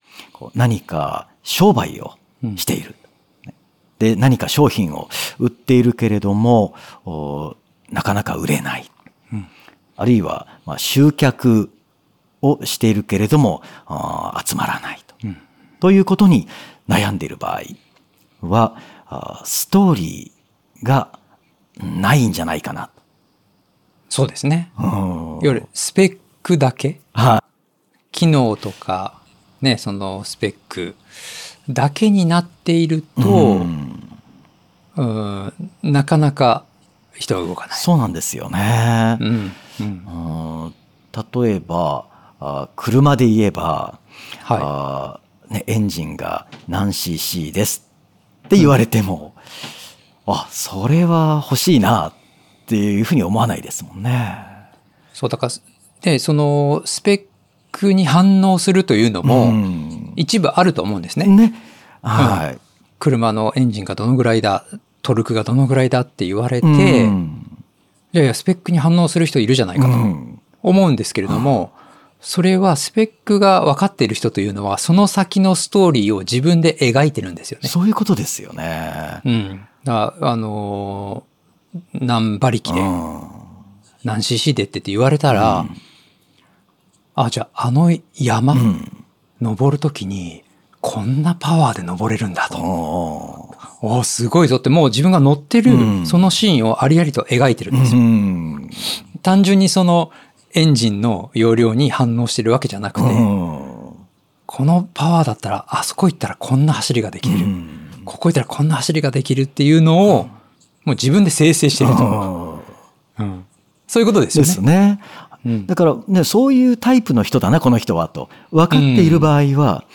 この音声講座は上記の「カートに入れる」ボタンから個別購入することで聴くことができます。